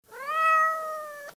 Cat Meowing